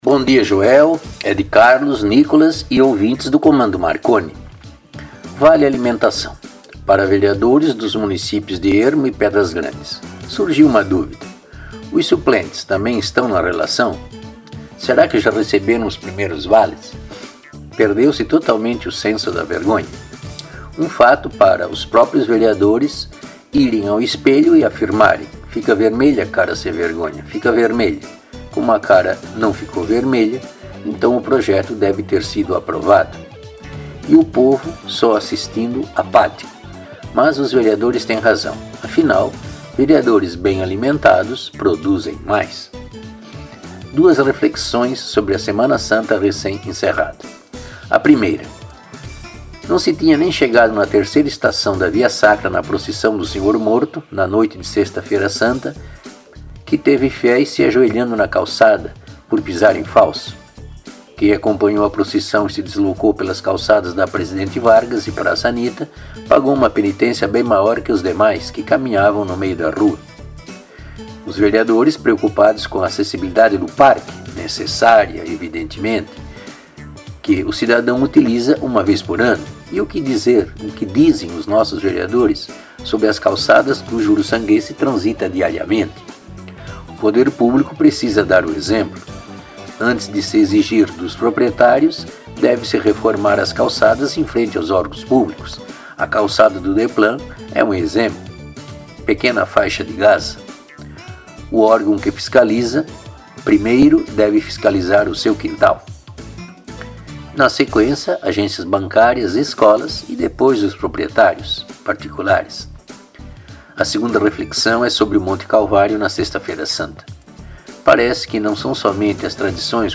A atração é apresentada de modo espirituoso e com certas doses de humorismo e irreverência, além de leves pitadas de ironia quando necessárias.